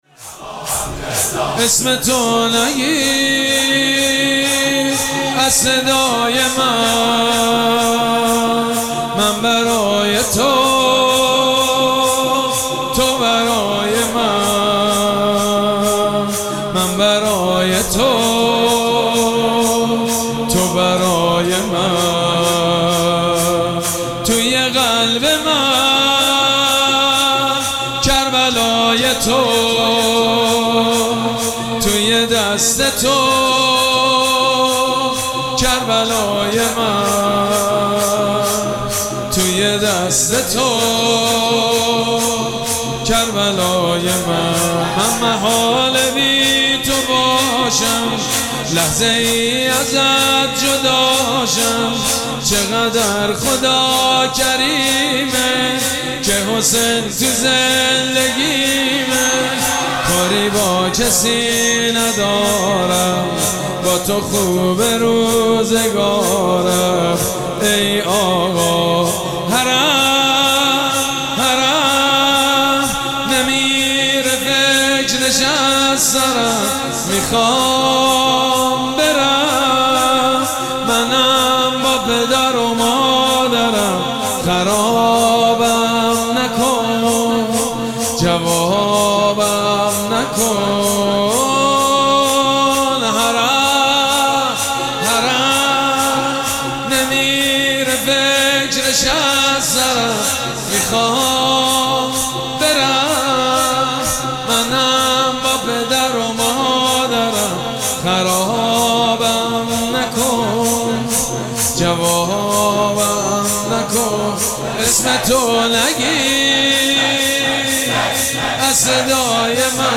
مراسم عزاداری شب ششم محرم الحرام ۱۴۴۷
شور
مداح
حاج سید مجید بنی فاطمه